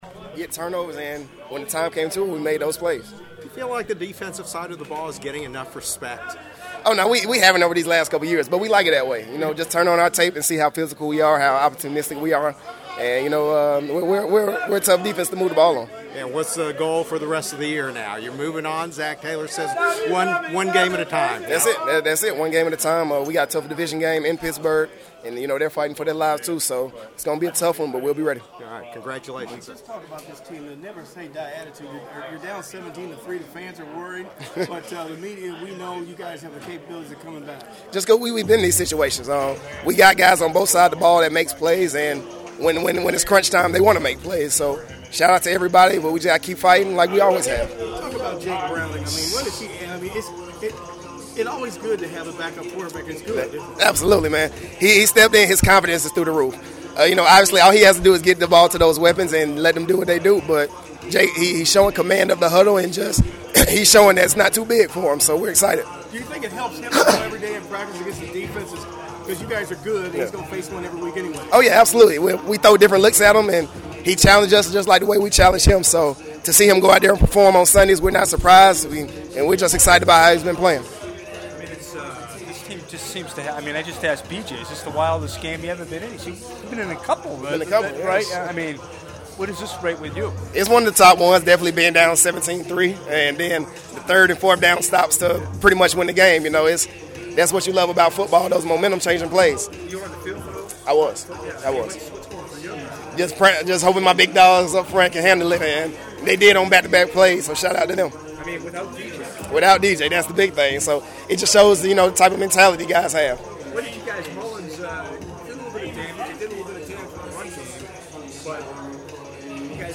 Bengals 27 Vikings 24 in OT; Mike Hilton made key plays on defense in win: Postgame Lockerroom Media Session